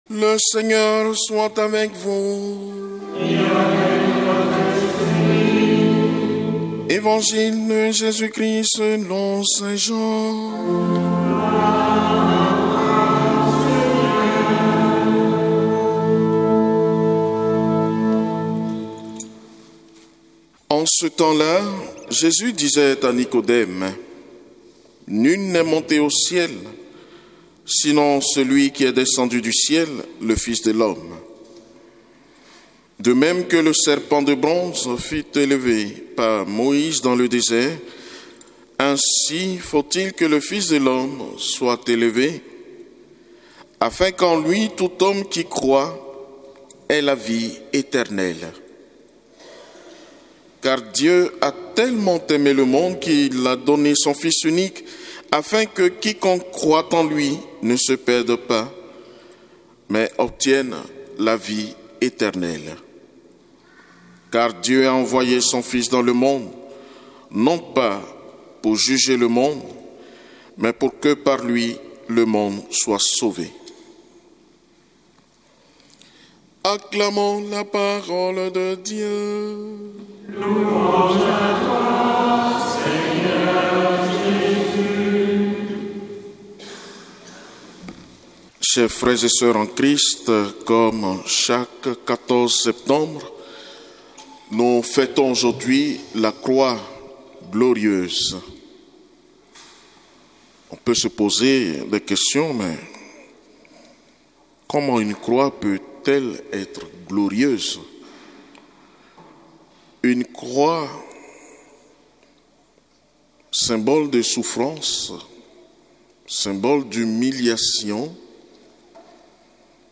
Évangile de Jésus Christ selon saint Luc avec l'homélie